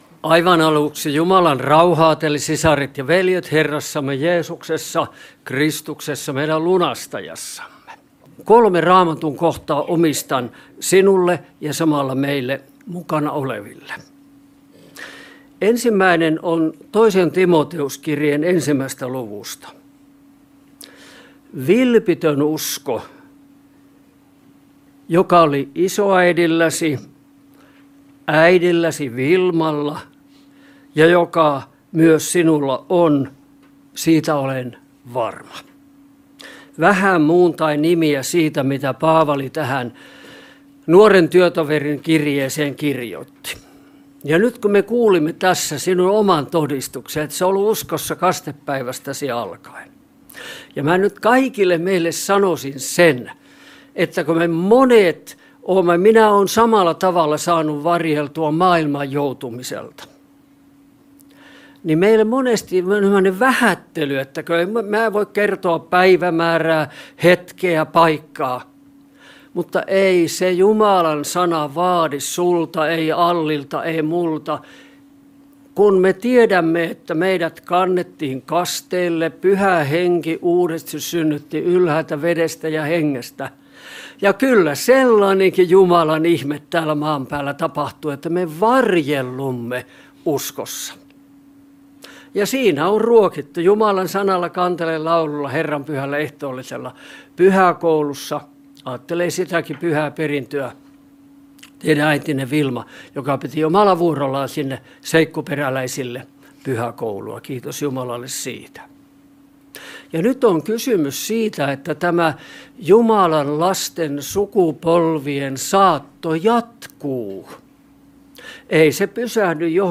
onnittelupuhe Kokkolassa